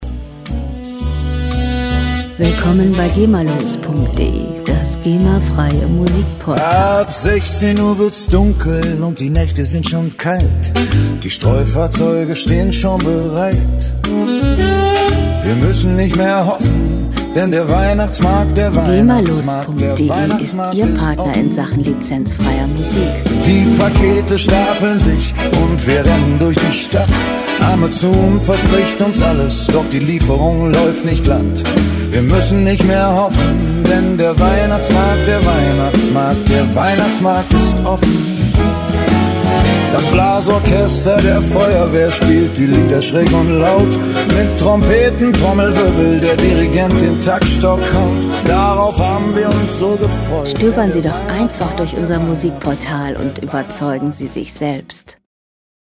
• Big-Band-Swing